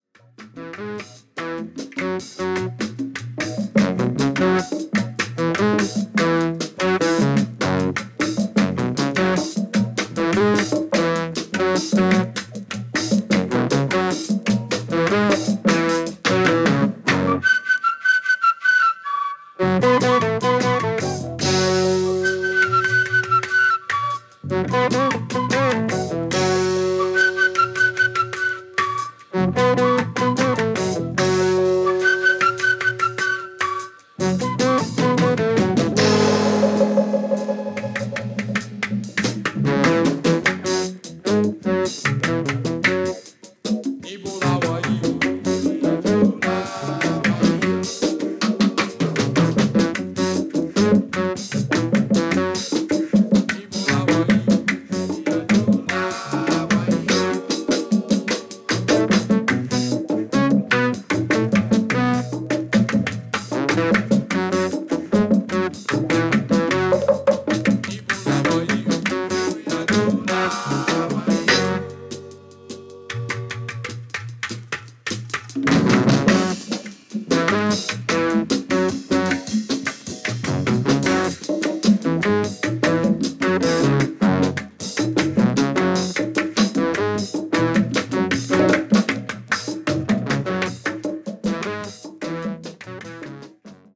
Guitar, Keyboards
Percussion, Flute, Vocals
African Drums,Vocals, Piano